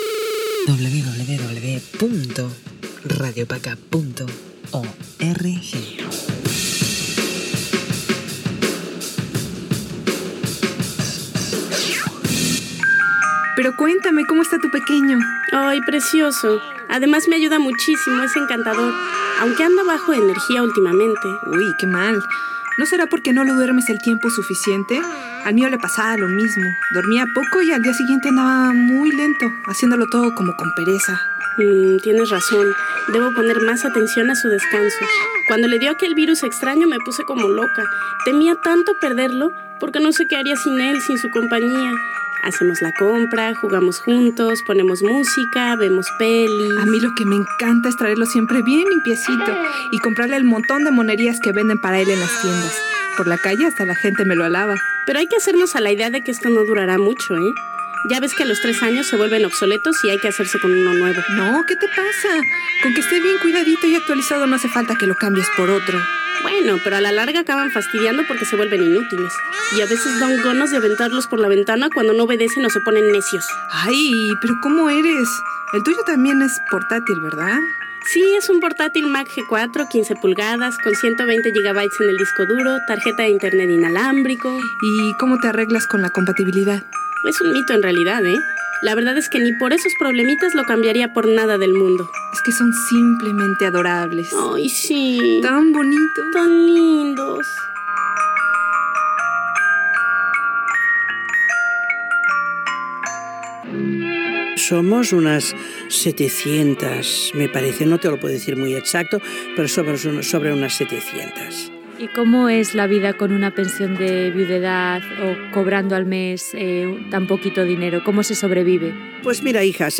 Diàleg sobre els ordinadors, amb doble sentit.
Identificació de la ràdio.